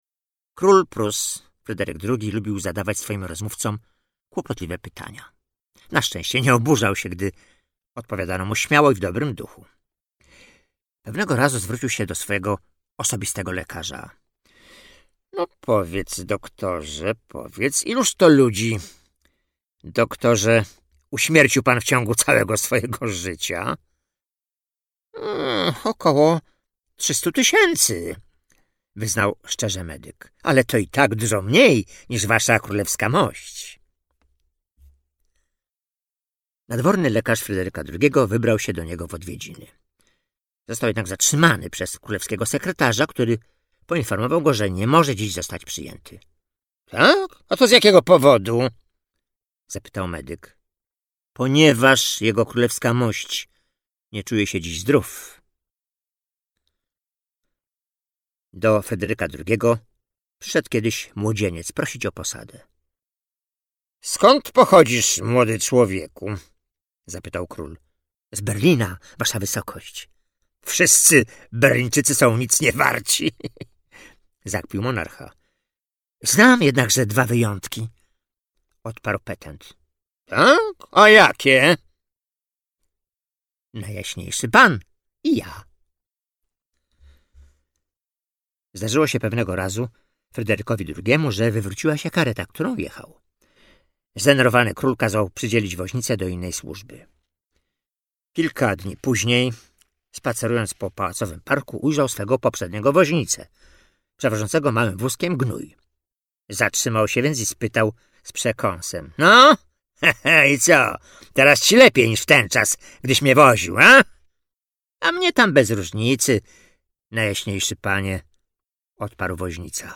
Najlepsze anegdoty o władcach - Audiobook mp3
Lektor